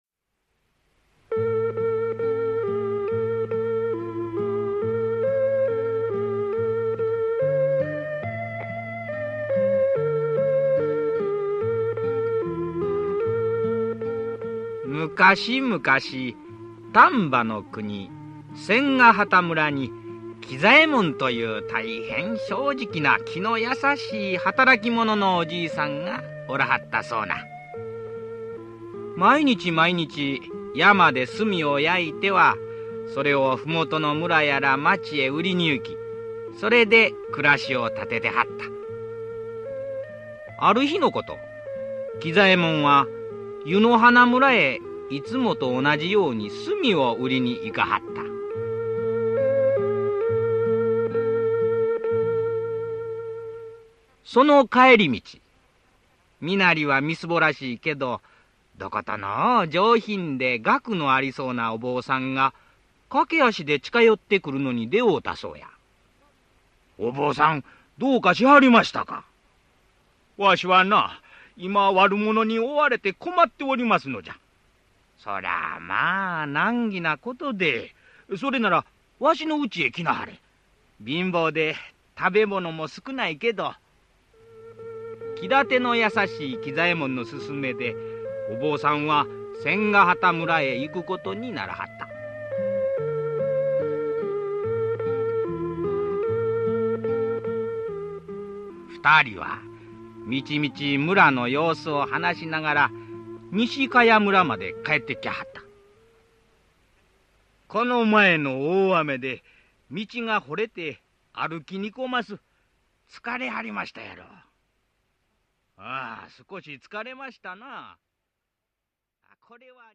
[オーディオブック] ゆるす